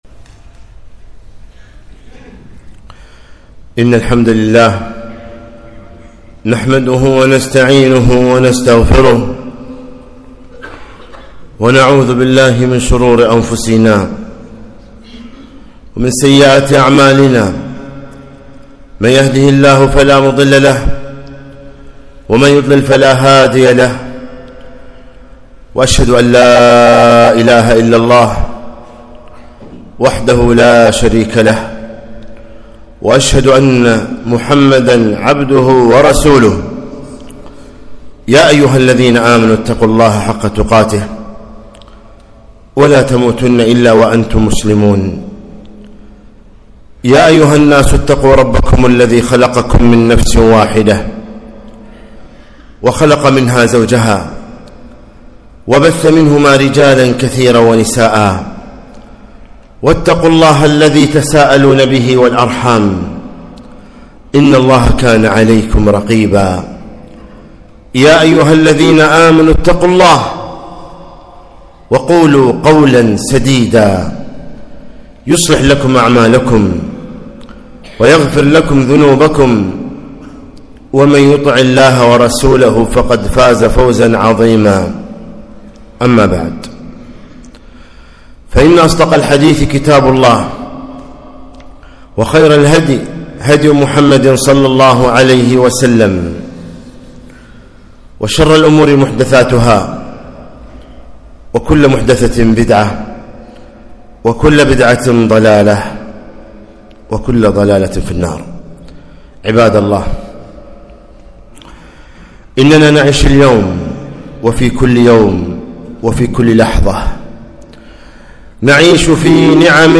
خطبة - ( وقليل من عبادي الشكور )